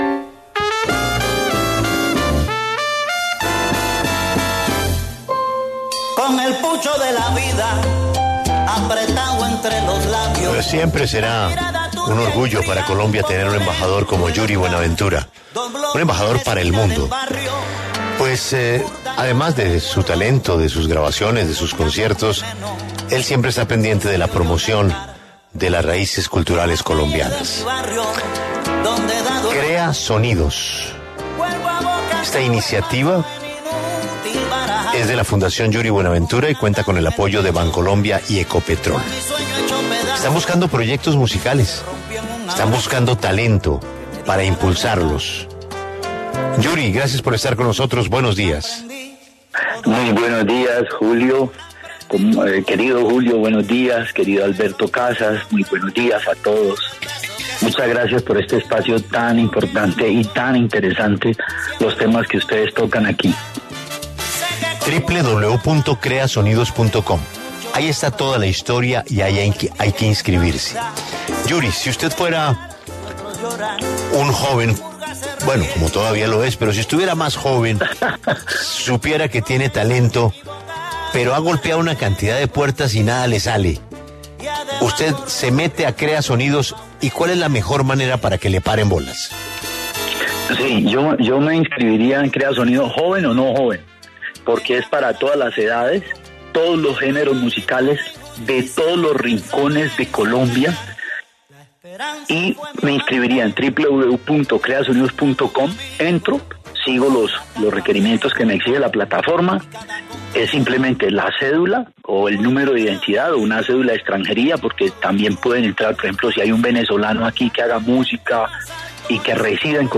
En diálogo con La W, el cantante Yuri Buenaventura conversó sobre ‘Crea Sonidos’, el proyecto que busca impulsar talentos musicales.